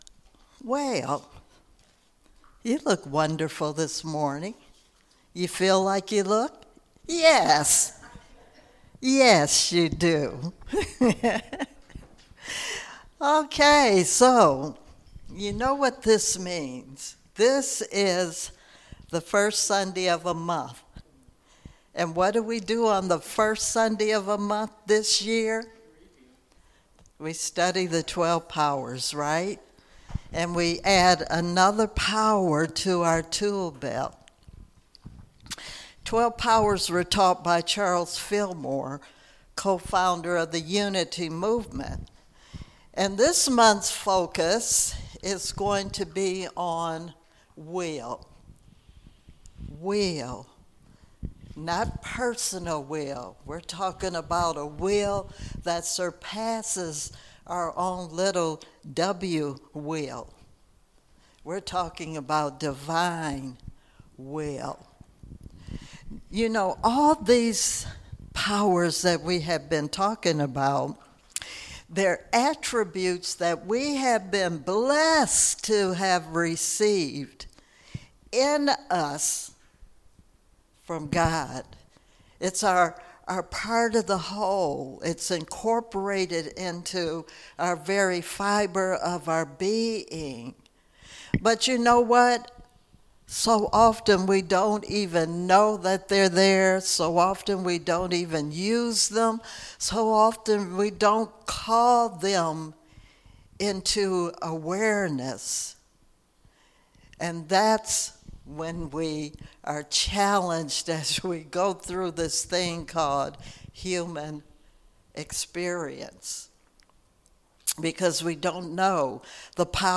Series: Sermons 2021